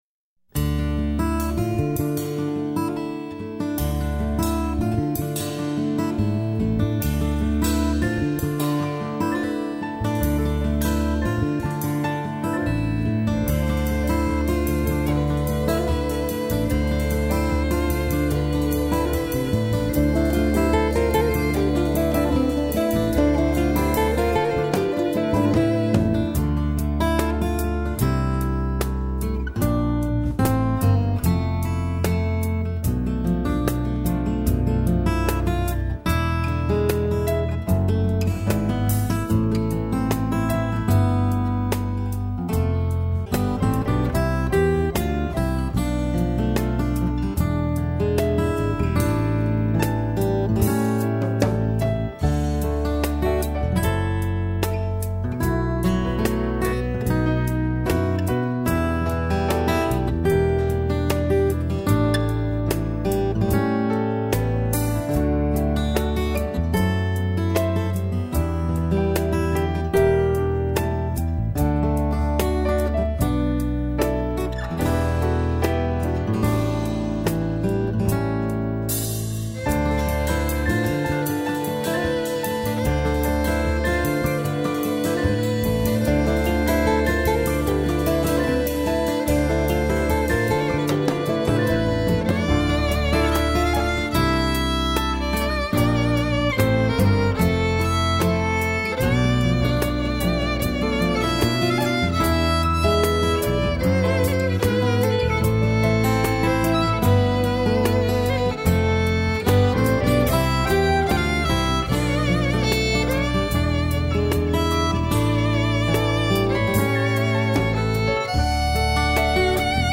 blends the modern genres of rock, blues, jazz and folk
electric violin
electric guitar
drums
bass guitar